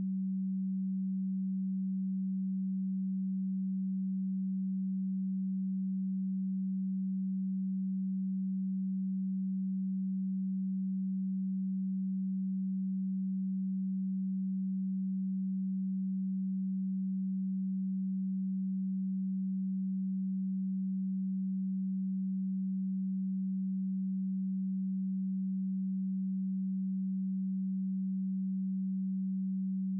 190Hz_-31.dB.wav